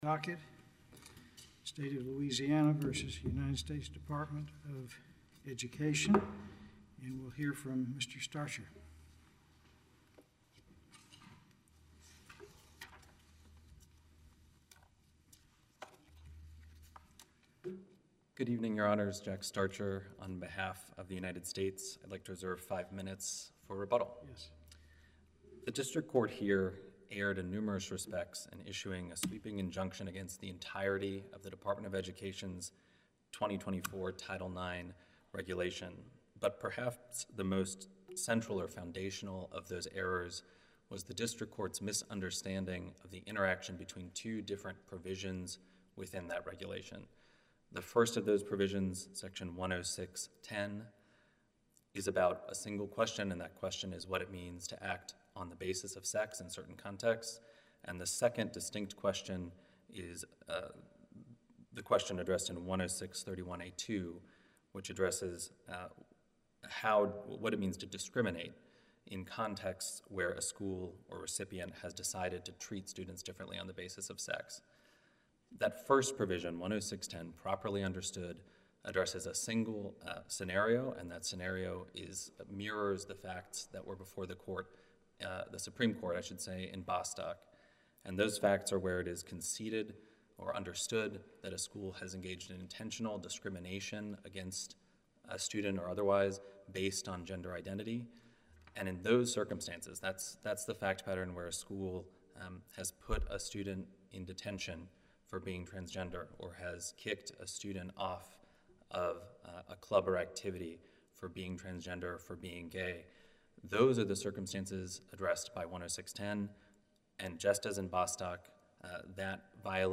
In an unusual evening argument, a three-judge panel of the furthest-right appeals court in the nation — that covers Louisiana, Mississippi, and Texas — began arguments in a case over a Louisiana-led, multi-state challenge to the Biden administration’s Title IX sex discrimination educational rule at 5:00 p.m. CT Monday.